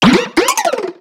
Cri de Maracachi dans Pokémon X et Y.